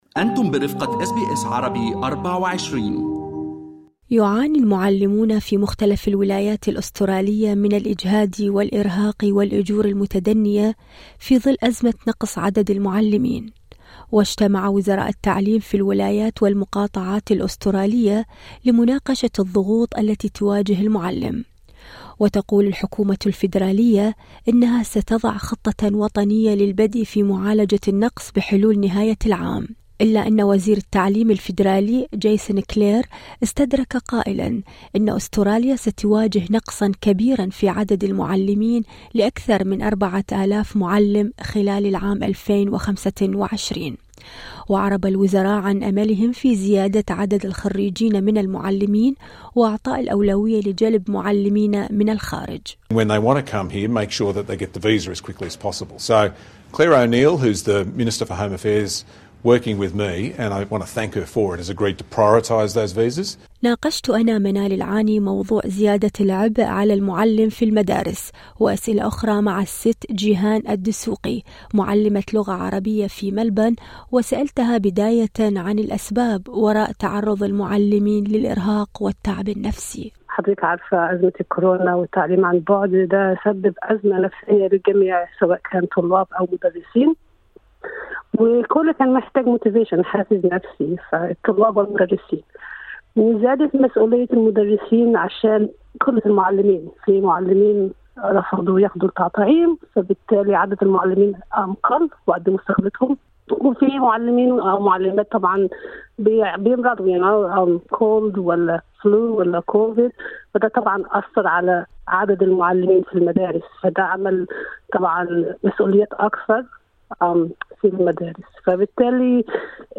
Arabic Teacher